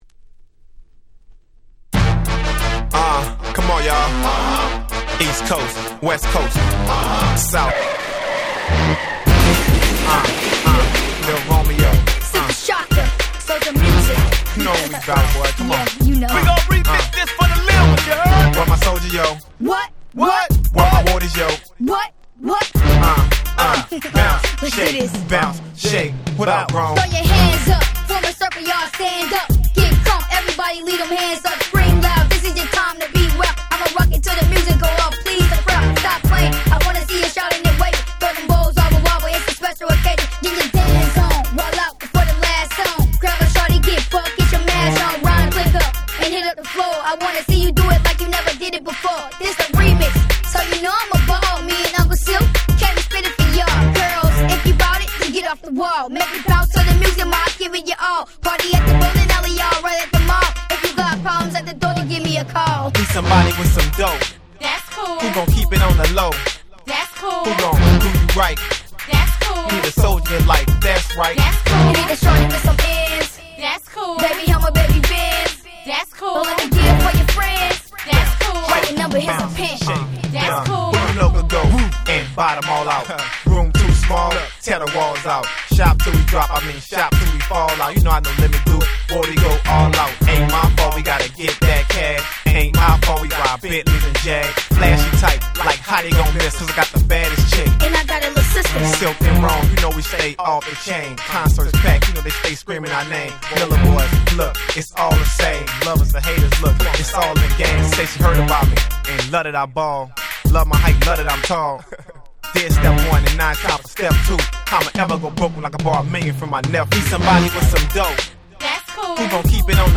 00' Smash Hit Southern Hip Hop !!